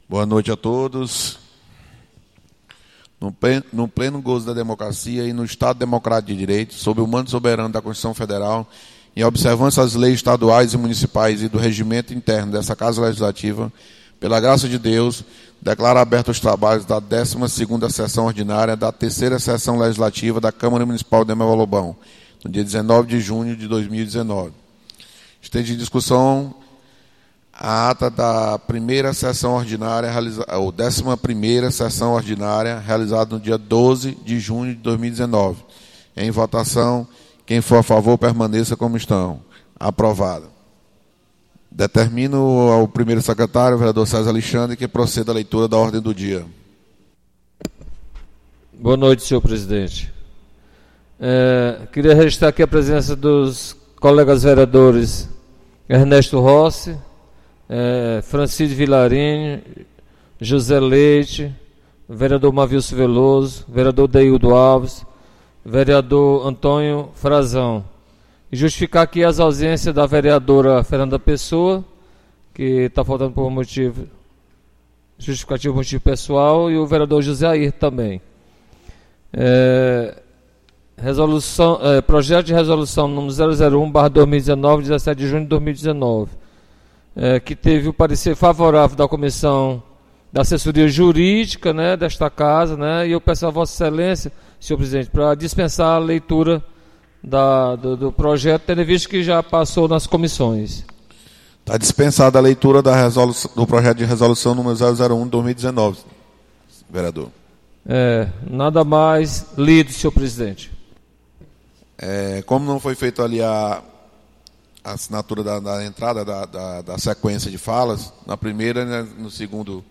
12ª Sessão Ordinária 19 de Junho